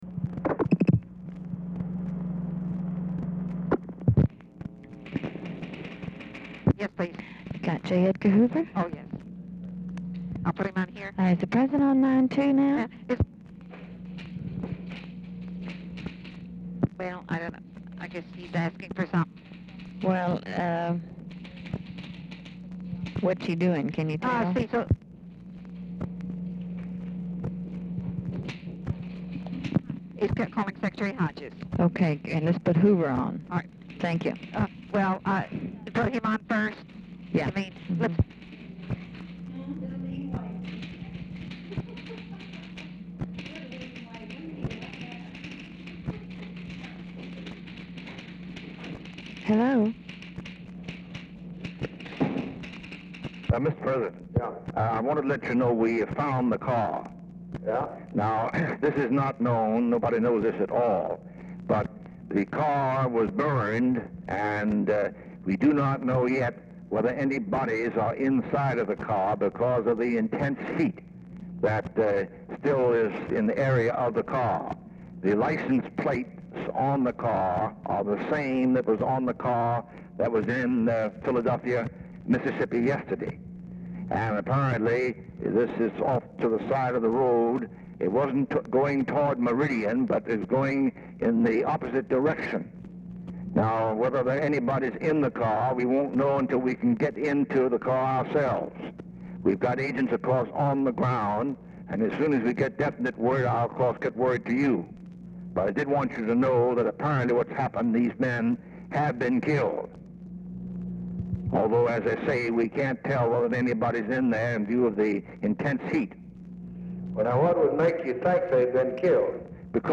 Telephone conversation # 3837, sound recording, LBJ and J. EDGAR HOOVER, 6/23/1964, 4:05PM | Discover LBJ
SECRETARY, OPERATOR CONVERSATION PRECEDES CALL
Format Dictation belt
Other Speaker(s) OFFICE SECRETARY, TELEPHONE OPERATOR